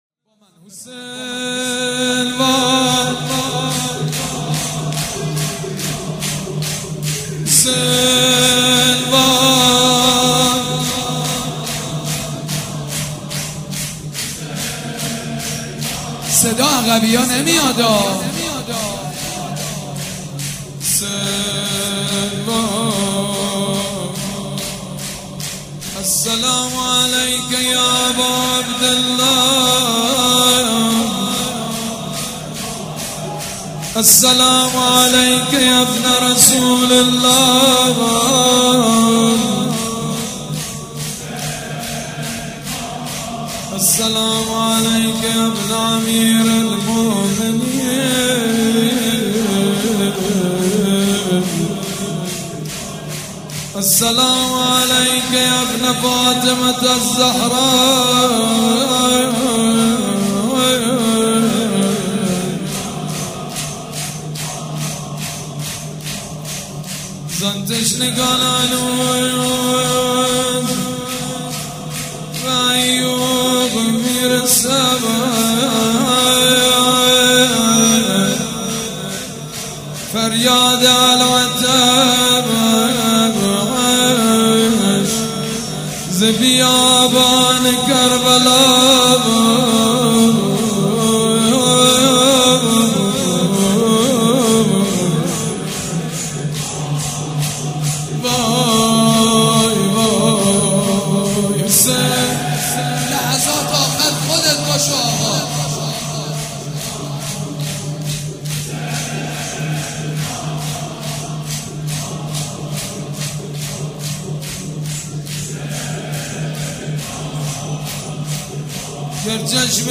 شب هفتم محرم95/هیئت ریحانه الحسین(س)